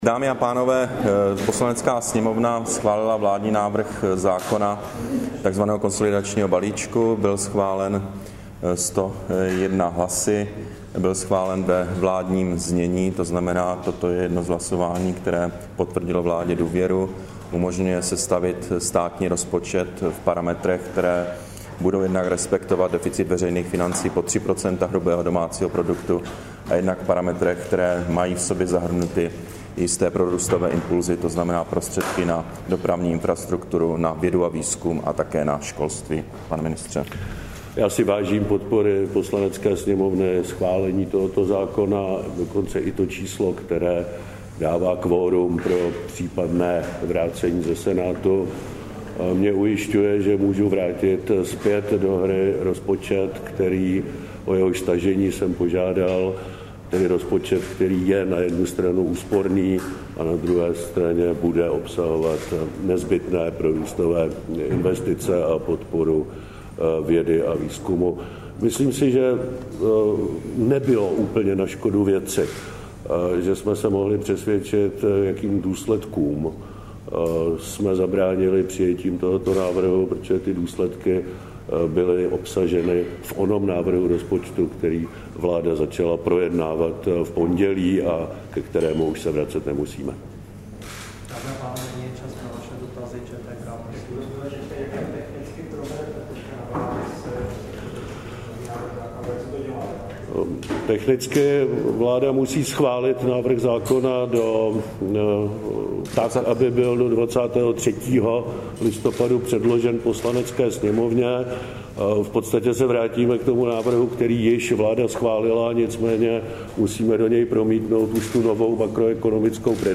Brífink premiéra Petra Nečase a ministra financí Miroslava Kalouska ke schválenému konsolidačnímu balíčku, 7. listopadu 2012